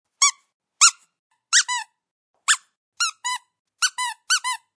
Download Toy sound effect for free.
Toy